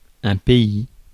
Ääntäminen
France (Paris): IPA: [ɛ̃ pe.ji]